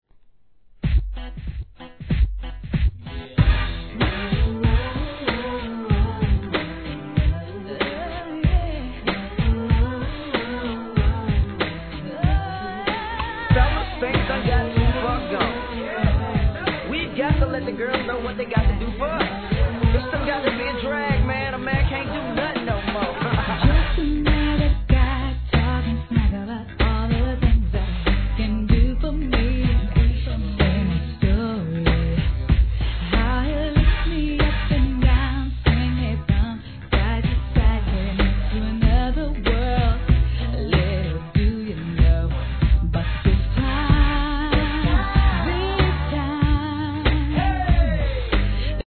HIP HOP/R&B
その親譲りのSOUL有るヴォーカルがこの1994年という時代の流行に見事にマッチ!!